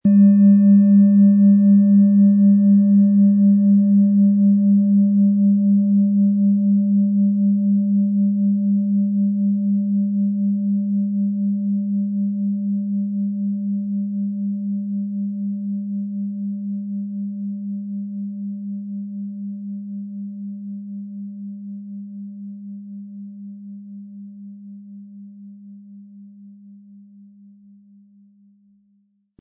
Tibetische Universal-Klangschale, Ø 23,4 cm, 1100-1200 Gramm, mit Klöppel
Wir haben ebendiese Klangschale beim Aufnehmen getestet und den subjektiven Eindruck, dass sie sämtliche Körperregionen gleich stark zum Schwingen bringt.
Im Sound-Player - Jetzt reinhören können Sie den Original-Ton genau dieser Schale anhören.
Lieferung inklusive passendem Klöppel, der gut zur Klangschale passt und diese sehr schön und wohlklingend ertönen lässt.